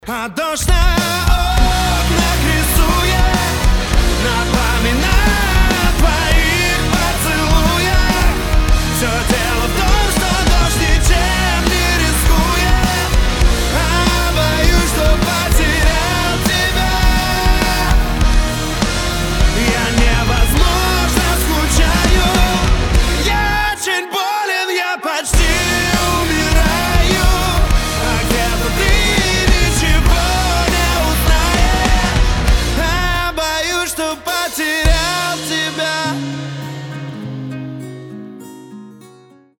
• Качество: 320, Stereo
громкие
Cover
Pop Rock
красивый вокал